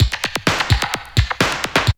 ZG2BREAK10#8.wav